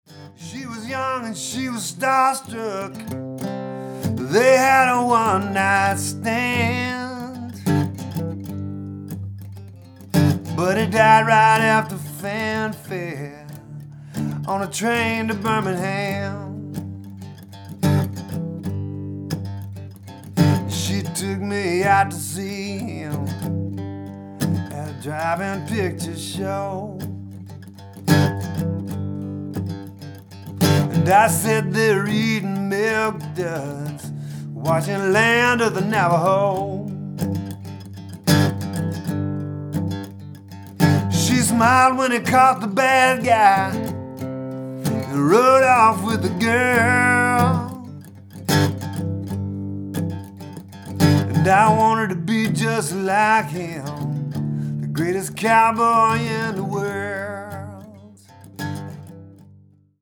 acoustic guitar